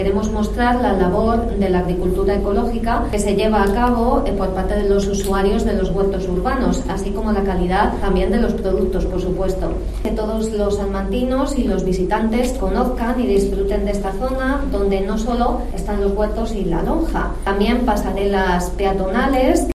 La Concejala de Medio Ambiente, Miryam Rodríguez, apunta el objetivo de este concurso.